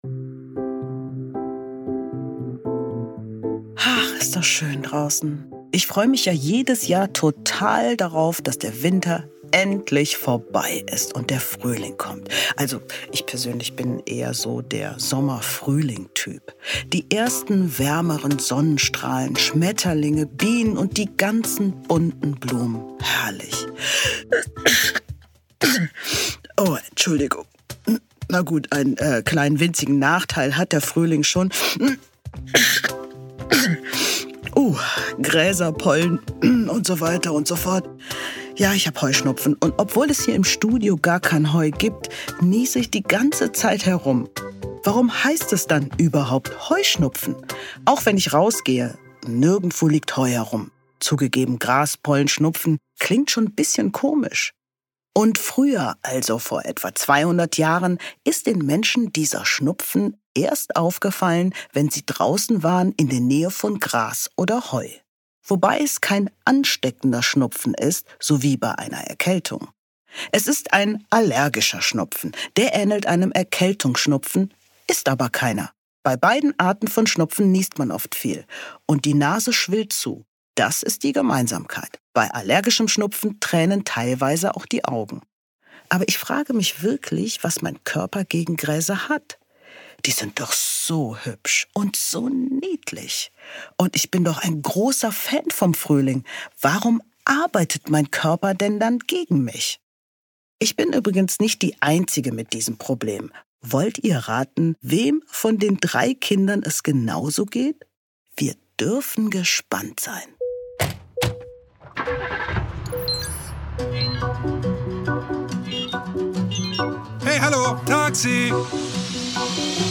Taxi ins Mich | Der Hörspiel-Podcast für Kinder – Podcast
Shary Reeves, bekannt aus "Wissen macht Ah!", führt durch diese aufregenden Abenteuer und erklärt die medizinischen Fakten!